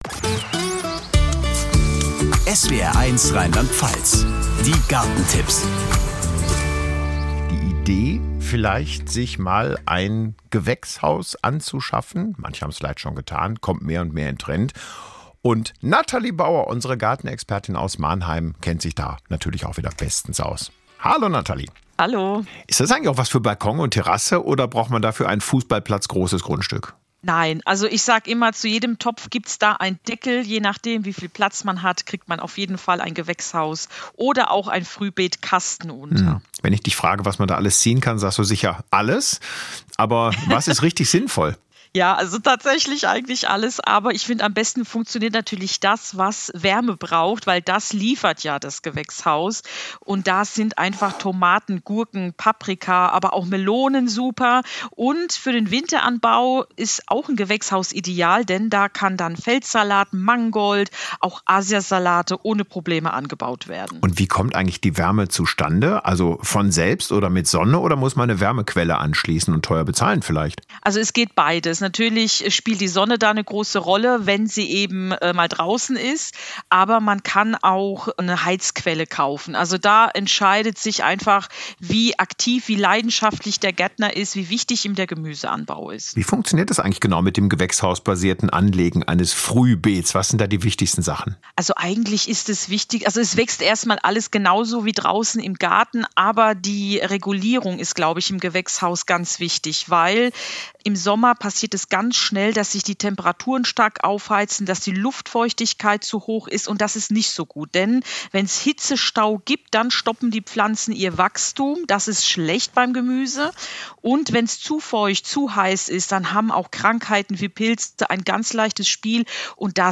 Gespräch mit